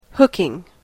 /ˈhʊkɪŋ(米国英語)/